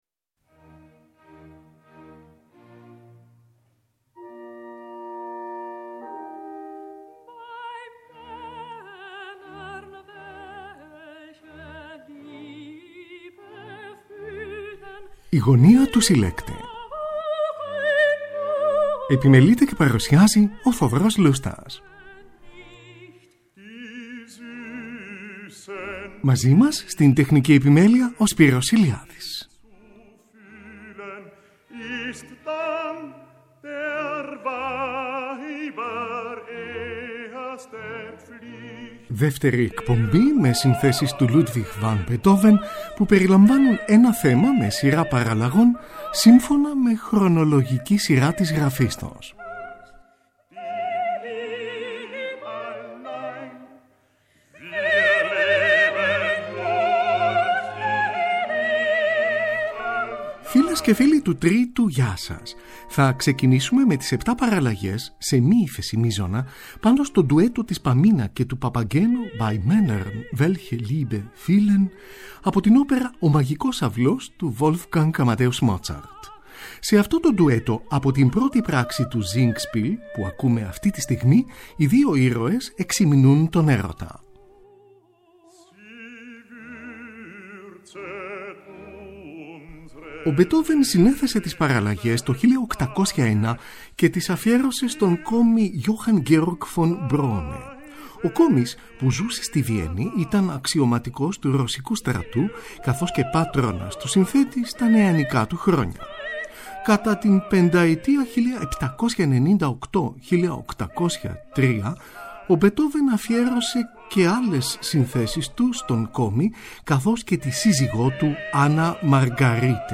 7 παραλλαγές για βιολοντσέλο και πιάνο, πάνω στο ντουέτο της Pamina και του Papageno BeiMännernwelcheliebefühlen, από την όπερα Ο μαγικός αυλός του Wolfgang Amadeus Mozart, WoO 46.
από ηχογράφηση της Γαλλικής Ραδιοφωνίας
6 παραλλαγές, για πιάνο, έργο 34.